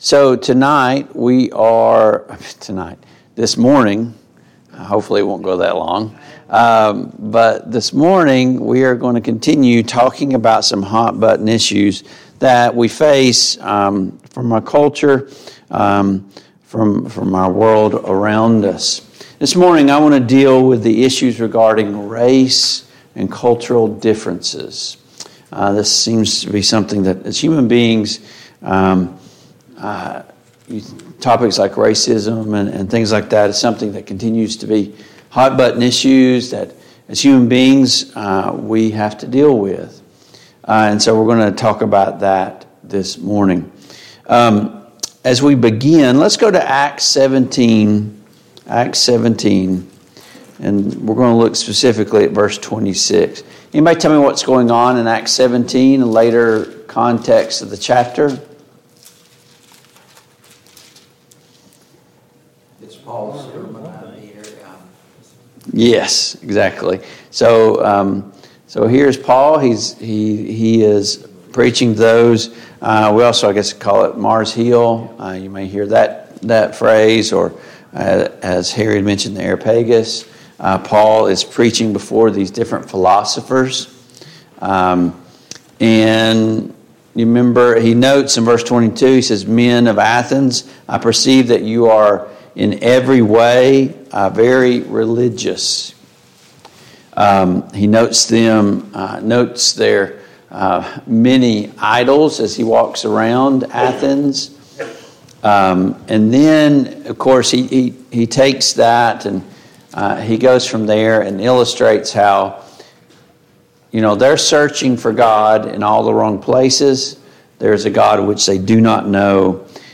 Sunday Morning Bible Class Topics